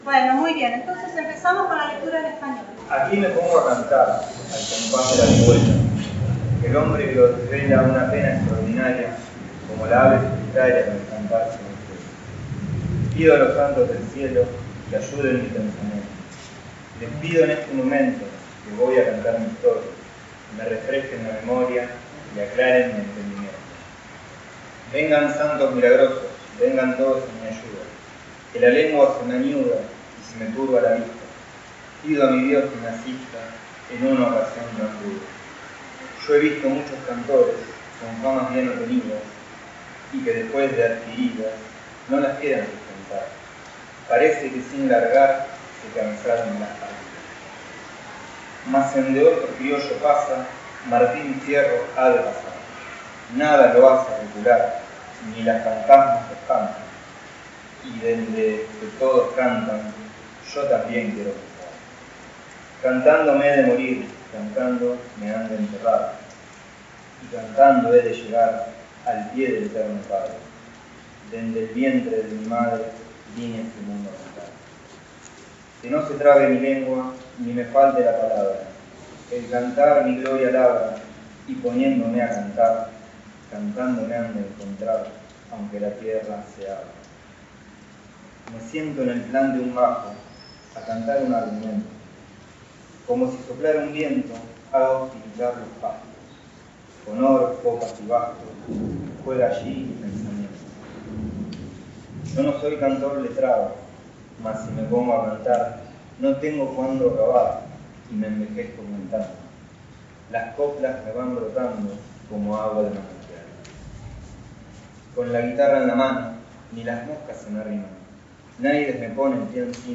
Evento: Jornadas Políglotas Martín Fierro (City Bell, 1° de diciembre de 2023)
Palabras claves: Poesía ; Literatura gauchesca